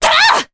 Maria-Vox_Attack4_jp.wav